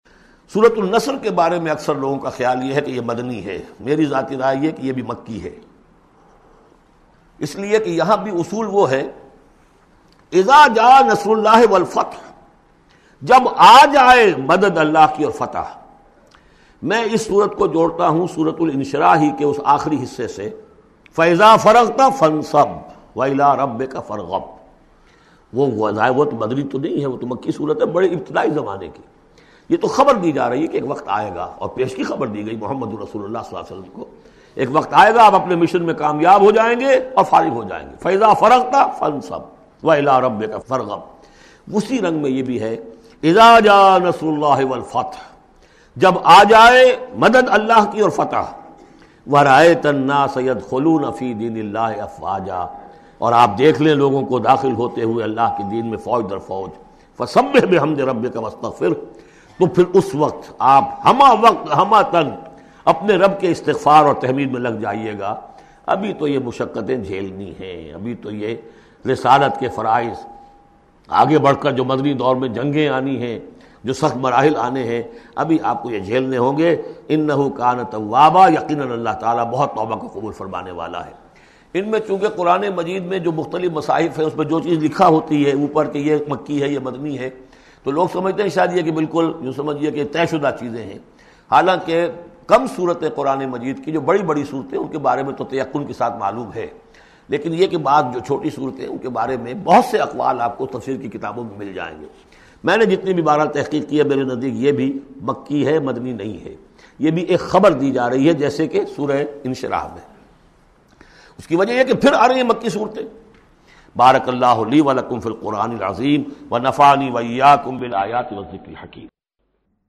Surah Nasr Audio Tafseer by Dr Israr Ahmed
Surah Nasr listen online mp3 audio tafseer in the voice of Dr Israr Ahmed.